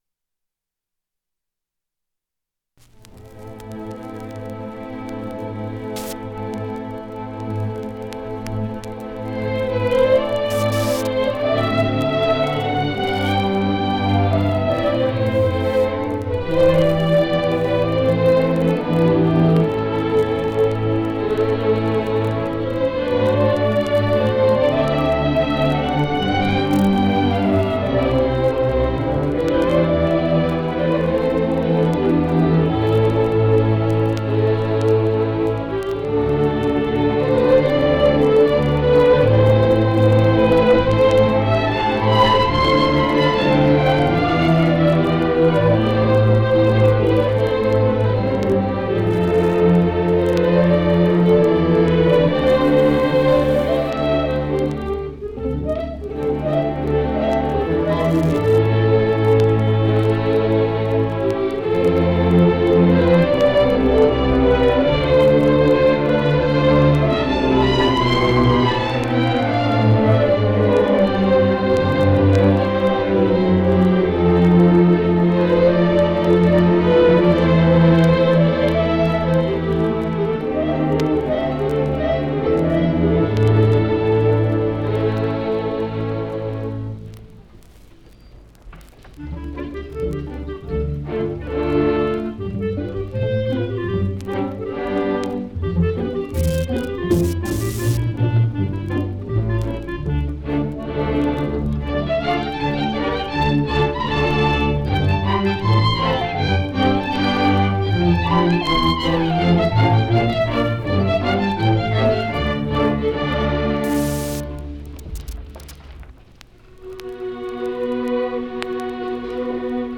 1960 Music in May orchestra performance recording · Digital Exhibits · heritage
2ac0aec145465ad7581bf5c43e029af99e489598.mp3 Title 1960 Music in May orchestra performance recording Description An audio recording of the 1960 Music in May orchestra performance at Pacific University. Music in May is an annual festival that has been held at Pacific University since 1948. It brings outstanding high school music students together on the university campus for several days of lessons and events, culminating in the final concert that this recording preserves.